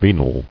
[ve·nal]